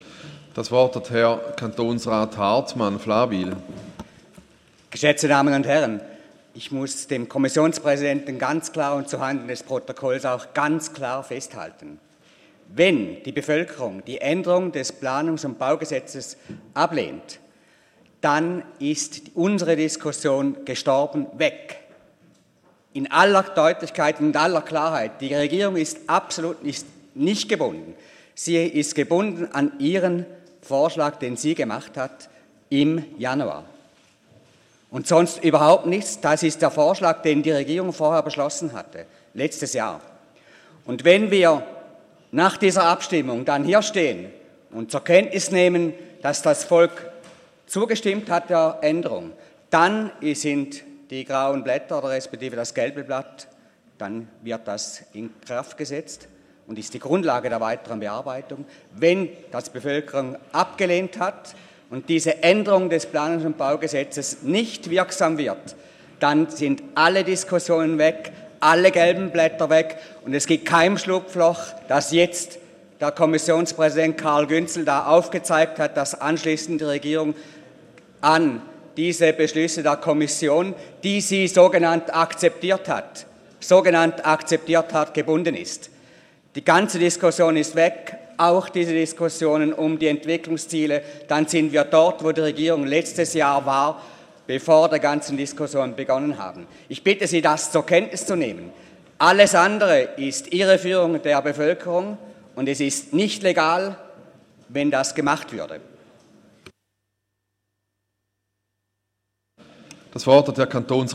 16.9.2015Wortmeldung
Session des Kantonsrates vom 14. bis 16. September 2015